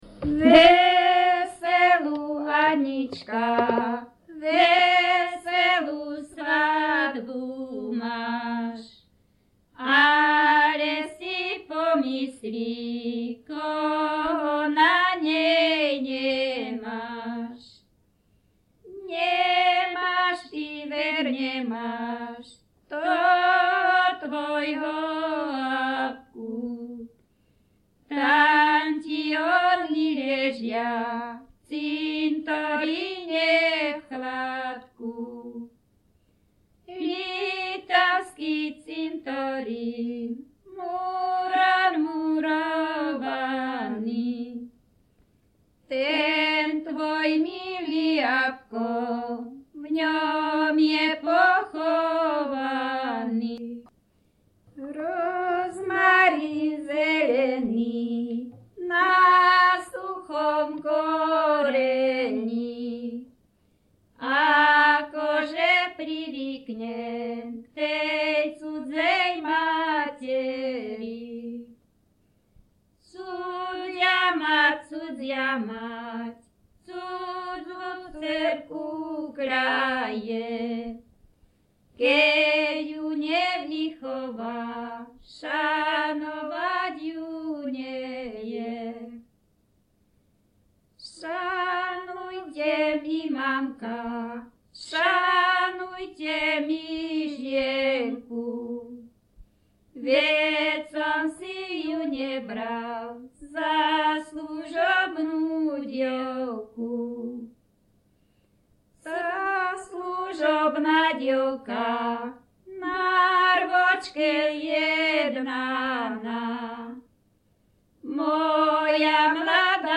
Descripton spev dvoch žien bez hudobného sprievodu
Place of capture Litava
Key words ľudová pieseň
svadobné piesne